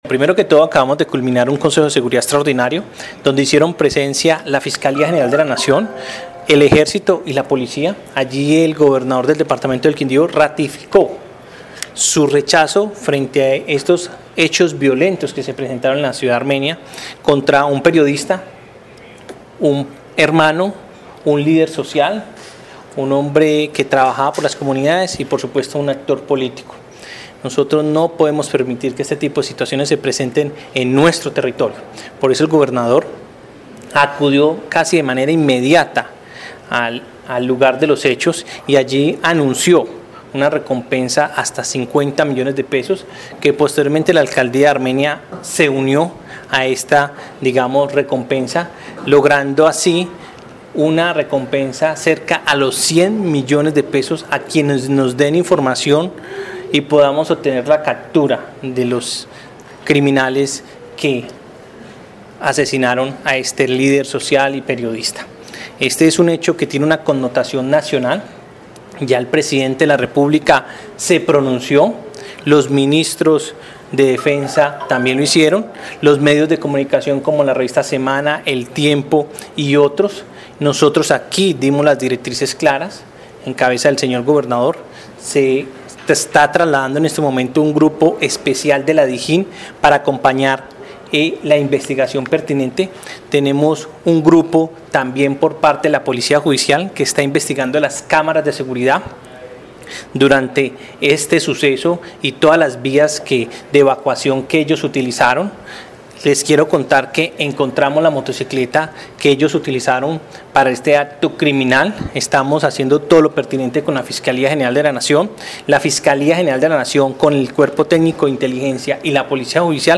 Jaime Andrés Pérez, secretario del Interior del Quindío